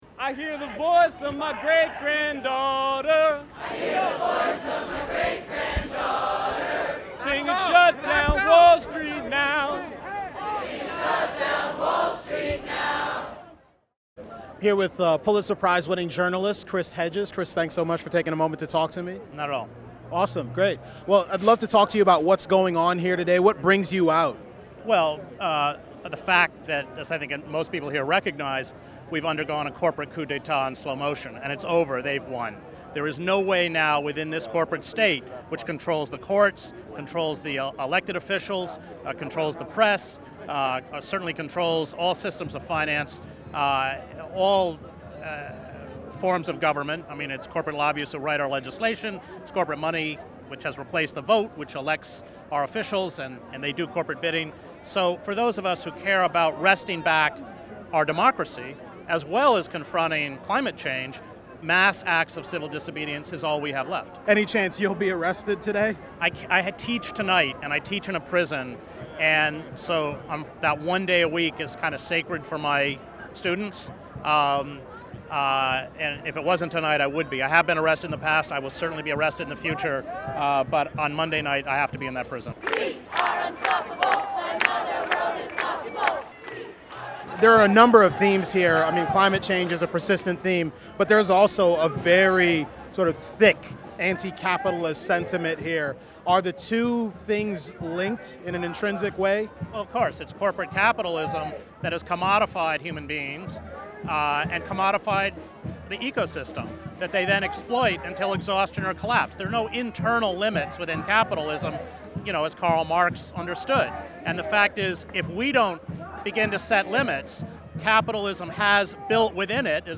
That tipped off a fiery exchange between the two journalists over the merits of free market capitalism that touched on Reconstruction, Aristotle, factories in Bangladesh, agribusiness, grocery shopping in New York City, and much much more.